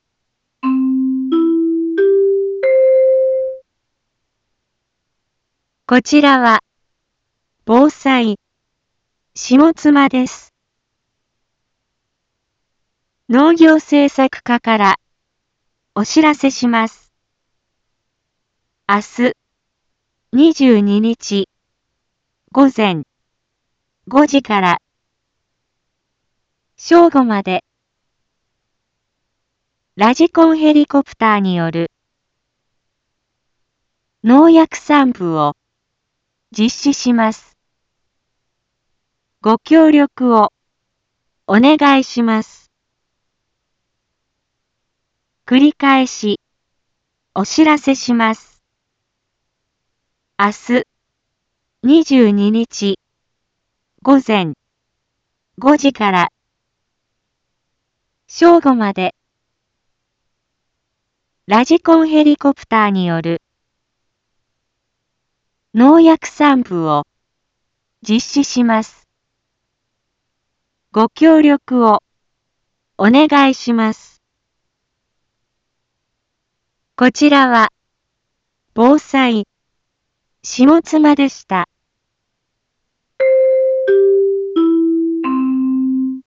一般放送情報
Back Home 一般放送情報 音声放送 再生 一般放送情報 登録日時：2024-04-21 12:31:27 タイトル：麦のラジコンヘリによる防除 インフォメーション：こちらは、ぼうさい、しもつまです。